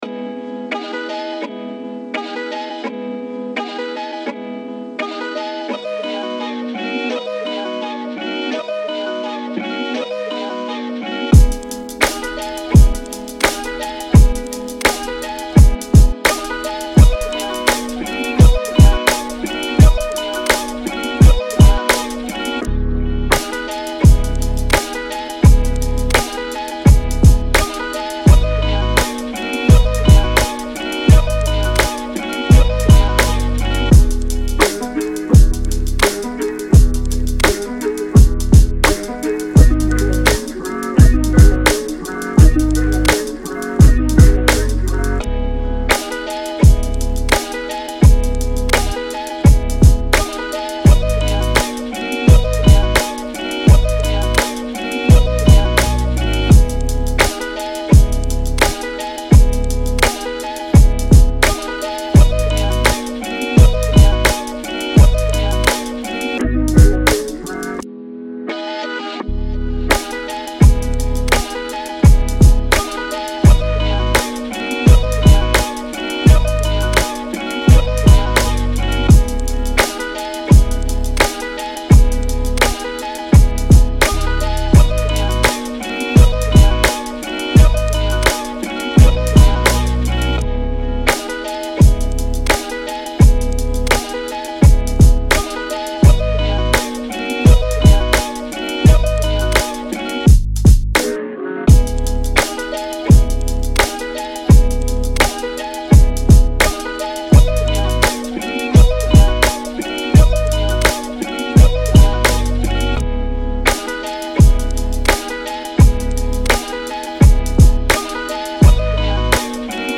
Hip Hop, R&B
Ab Minor